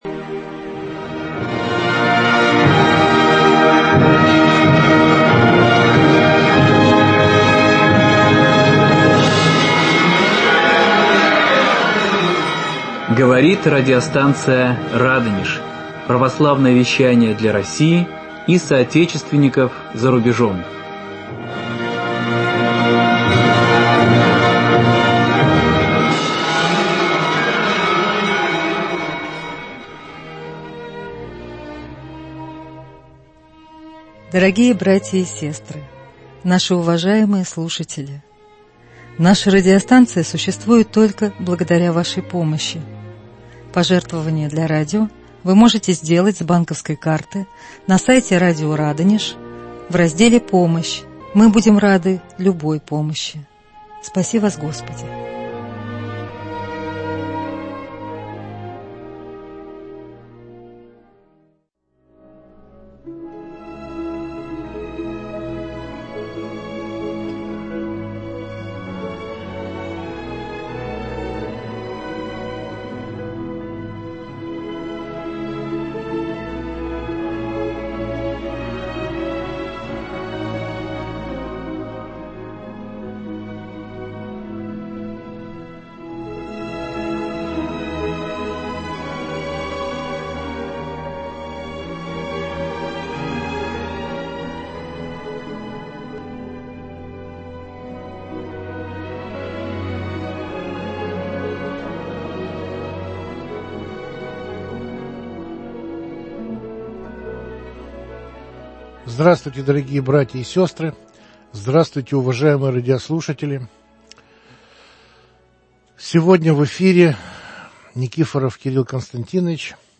Слушателей "Радонежа" благодарит за помощь руководитель боевого подразделения, сражающегося на купянском направлении. В репортаже из Дебальцево со словами благодарности и духовной поддержки к нам обращаются священники и миряне, которые уже не первый год стойко и мужественно переносят невзгоды военного времени.